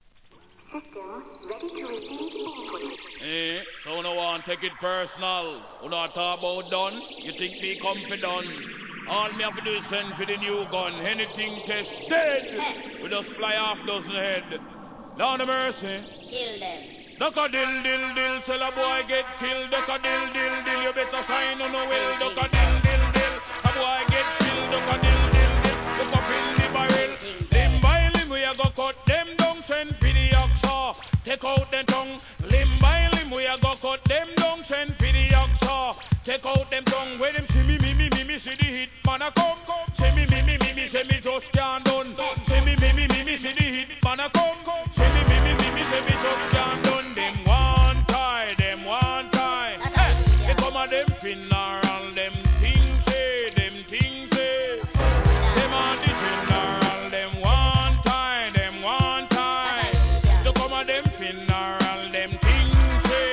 HIP HOP X REGGAEブレンド・シリーズ!!